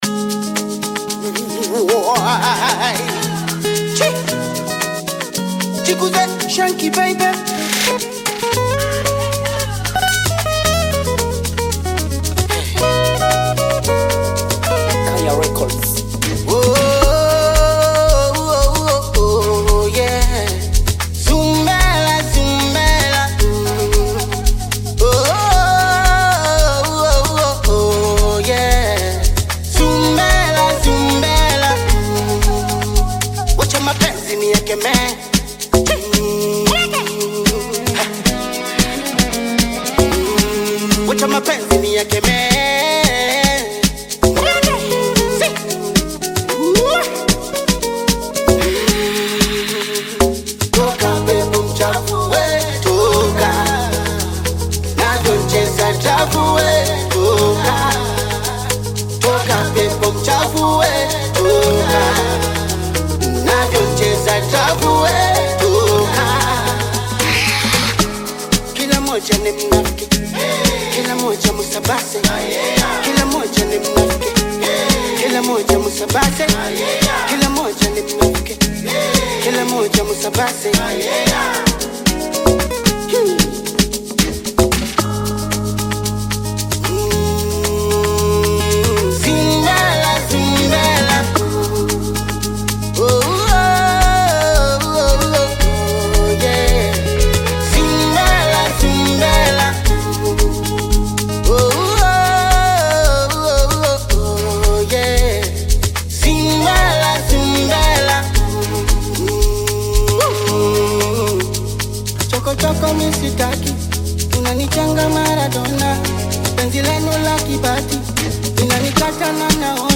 Kenyan artist, singer and songwriter
Bongo Flava song